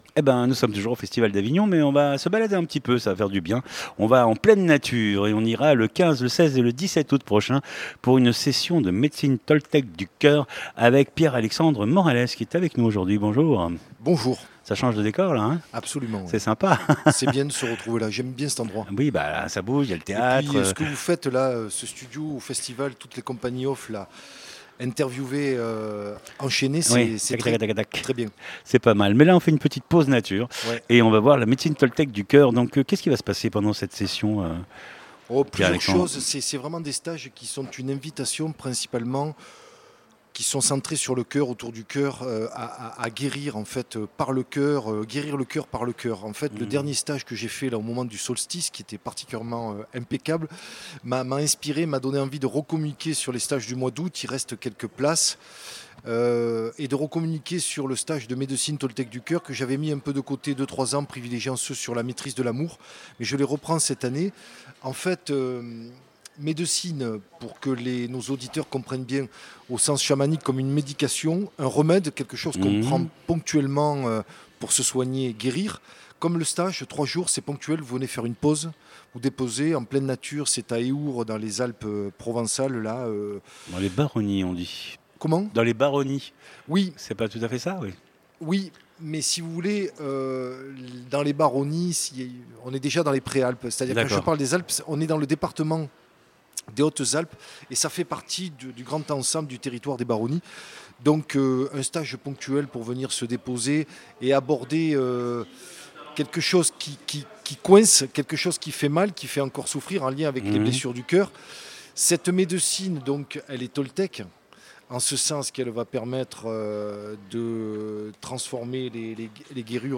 Bien être/Santé Interviews courtes